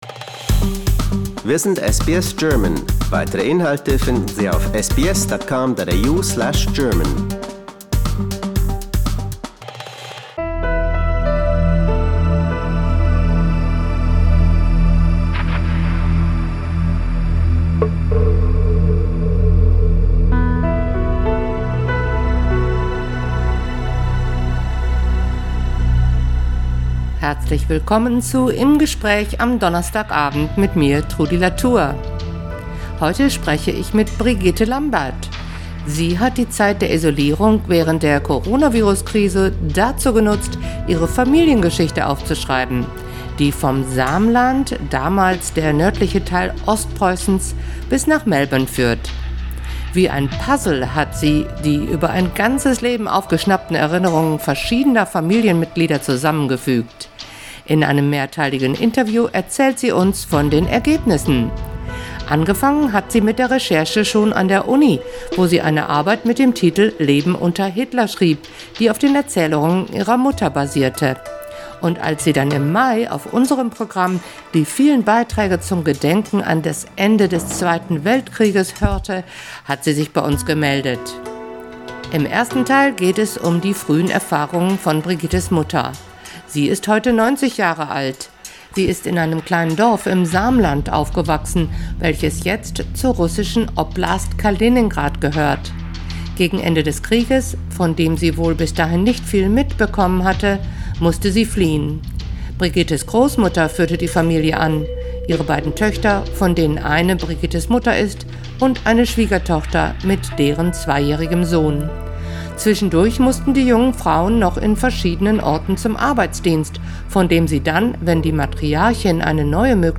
In a multi-part interview, she tells us about the results.